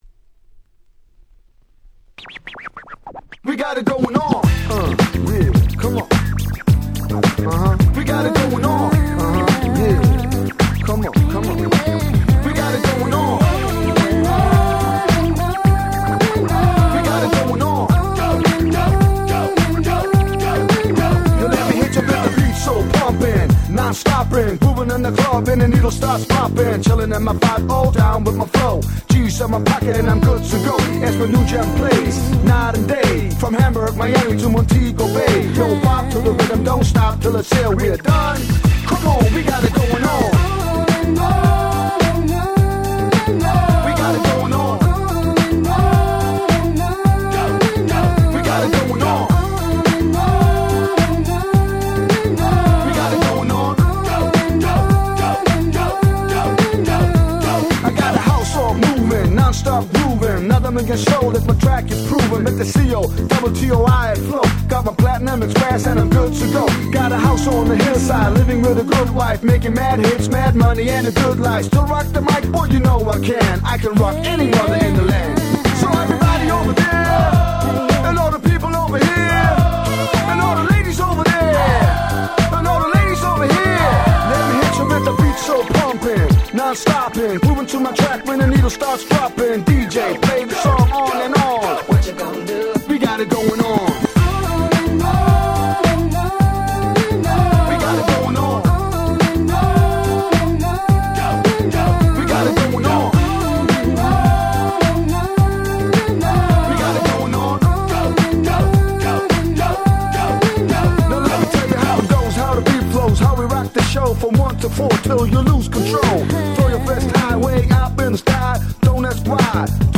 99' 大人気Euro歌ラップ！！
激キャッチー！！
「オーネノーネノーネノーン♪」の女性Vocalなサビが超キャッチー！！
Euro-G Dance Pop ダンスポップ